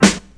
• Old School Sharp Hip-Hop Snare Sample G Key 141.wav
Royality free snare sound tuned to the G note. Loudest frequency: 2125Hz
old-school-sharp-hip-hop-snare-sample-g-key-141-mgo.wav